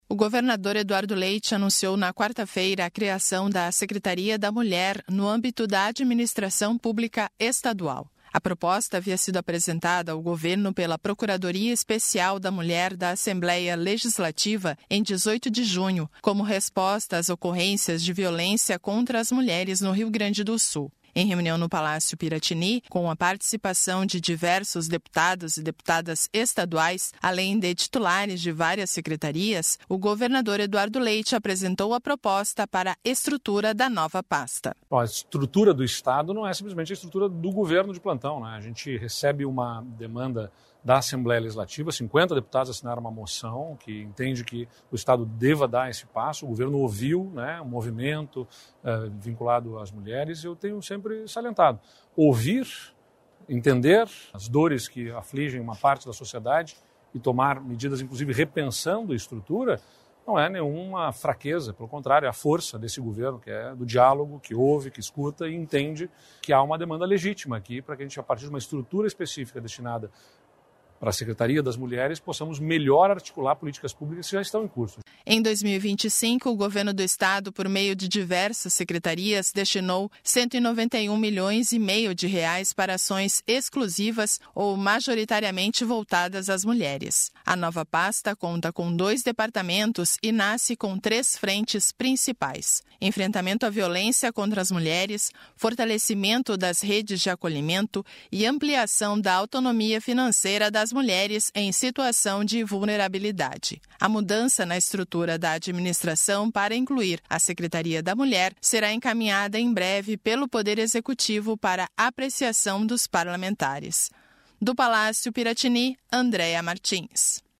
Governador Eduardo Leite anuncia criação da Secretaria da Mulher